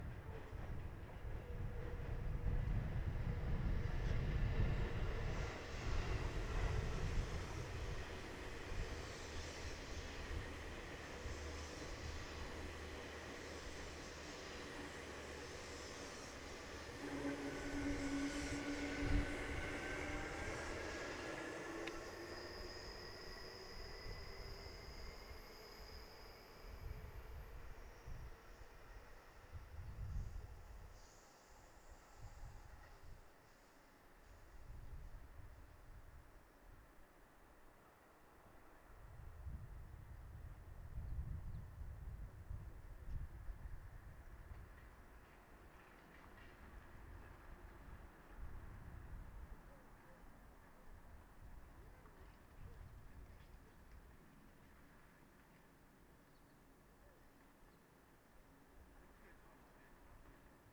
「清瀬立体の上の西武線の走行音」　2020年5月24日
F-99Aは、内蔵のウインドスクリーンだけでは風による吹かれ音があります。
レコーダー／PCM-D10